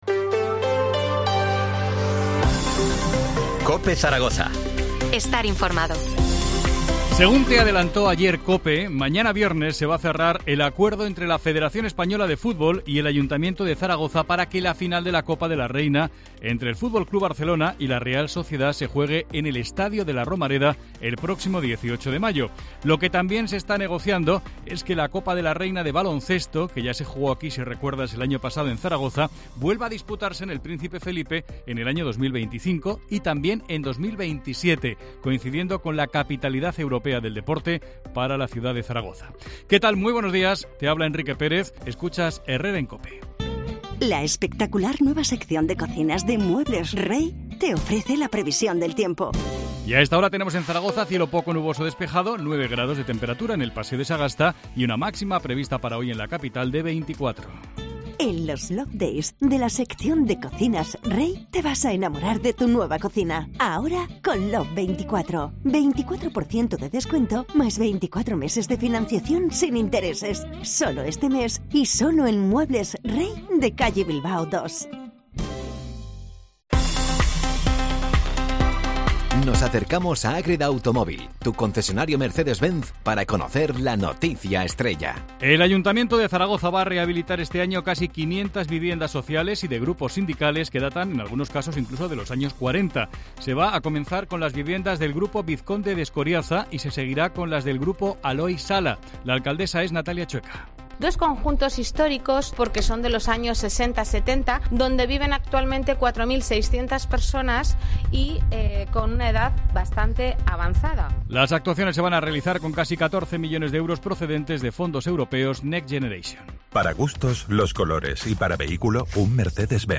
Titulares del día en COPE Zaragoza